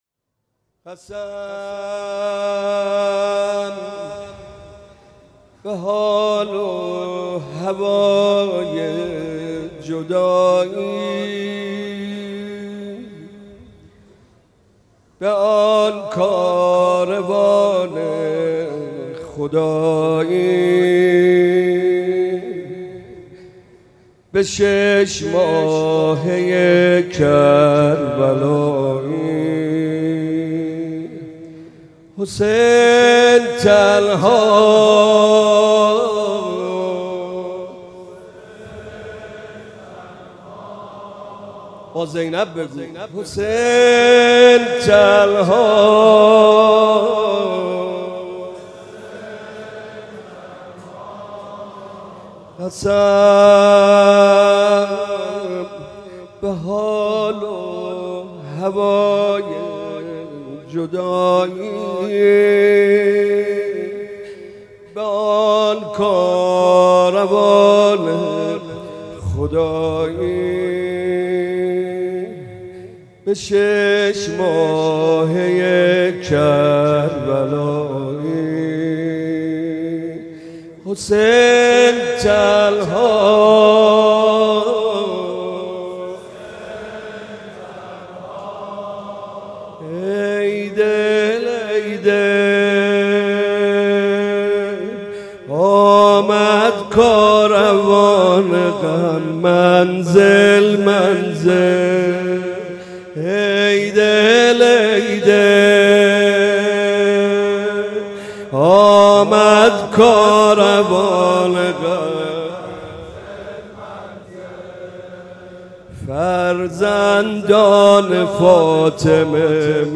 شب دوم محرم 98
نوحه
هیئت مکتب الزهرا (س)